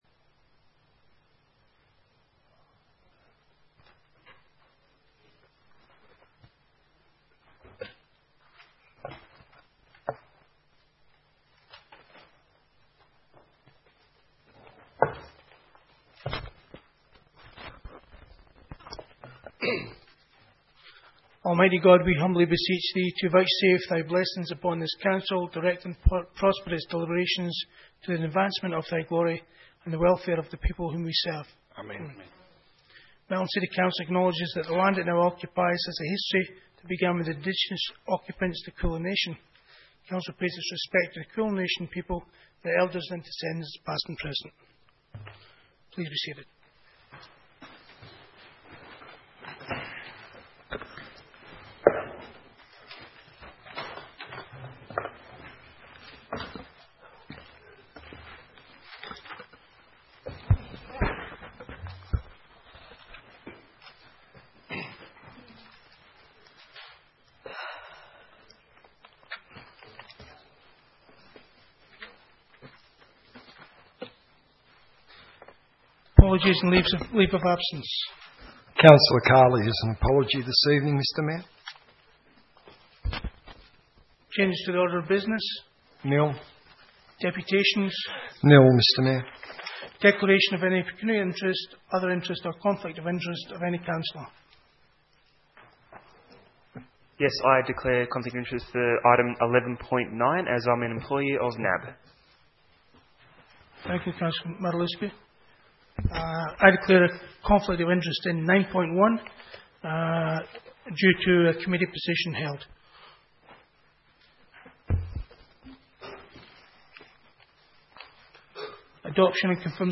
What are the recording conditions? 23 September 2014 - Ordinary Council Meeting